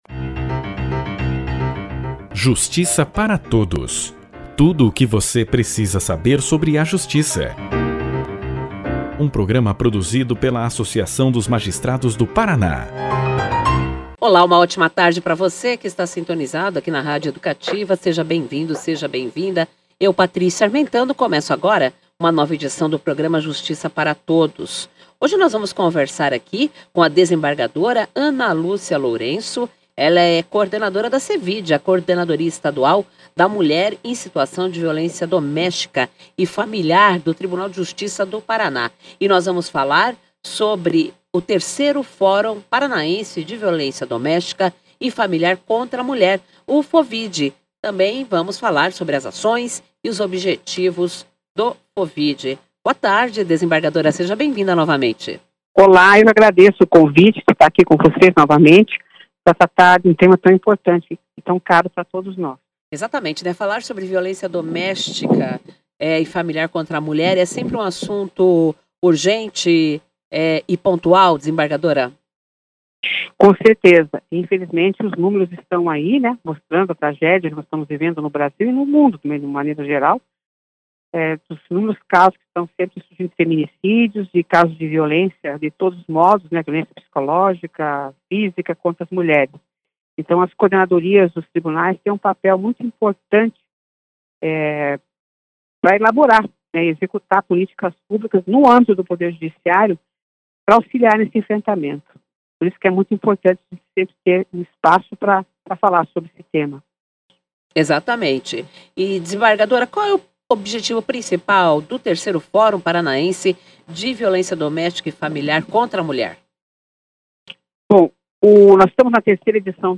O Programa de Rádio da AMAPAR, Justiça Para Todos, entrevistou a coordenadora da CEVID, a Desembargadora Ana Lúcia Lourenço, que explicou como o FOVID contribui para a qualificação dos atendimentos das vítimas, além de ser um espaço de reflexão e debate sobre a violência de gênero no sistema de Justiça.